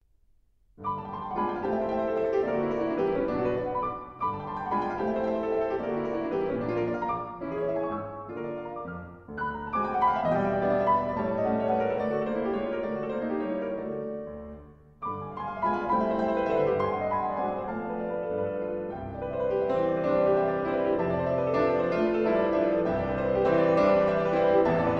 Prelude No. 14 in F sharp minor: Allegretto grazioso